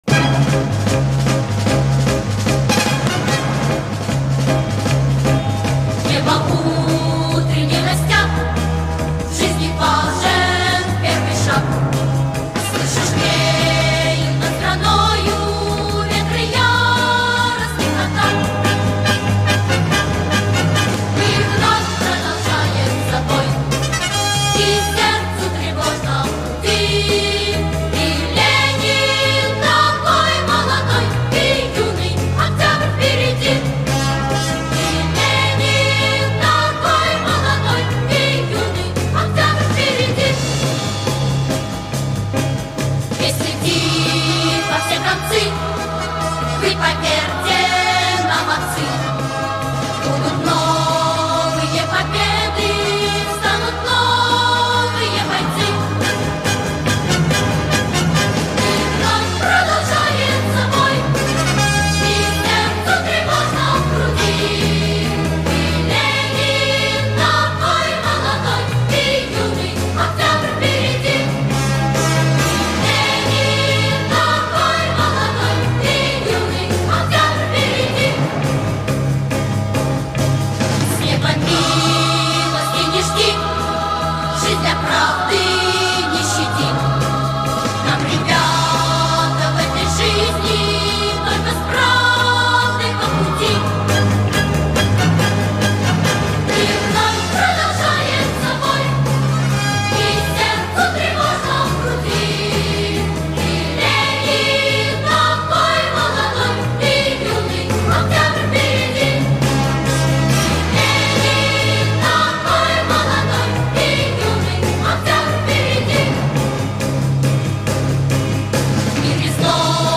slate Revolution/Video/Lenin is Young Again! [Ленин такой молодой!] - Soviet Patriotic Song